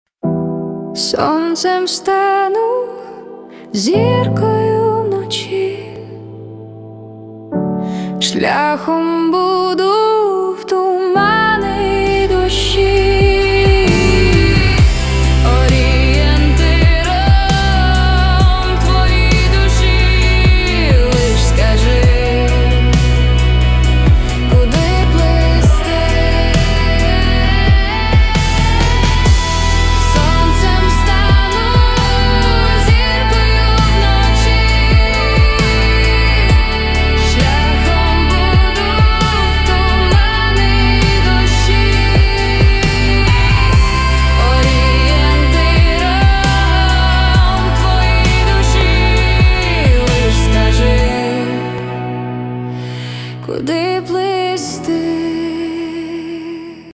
Танцевальные рингтоны